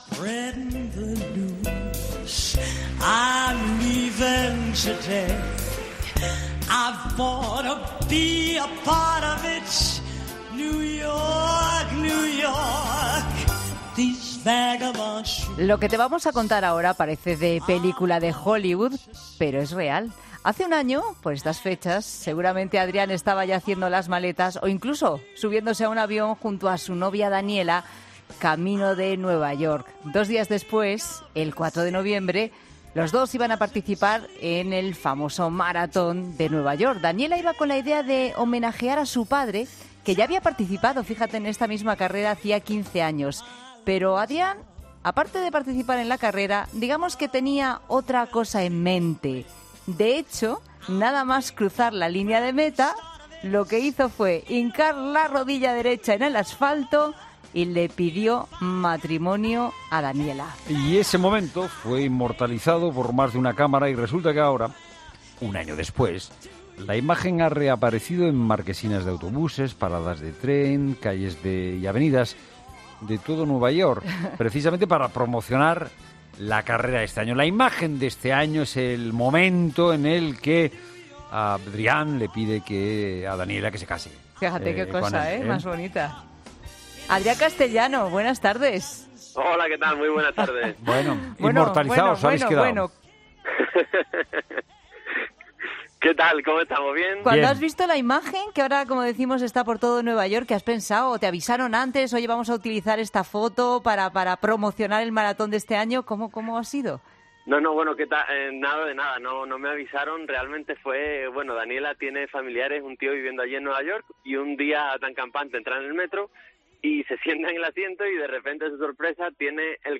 La pareja ha estado en ‘La Tarde’ y han explicado que desconocían la trascendencia de la imagen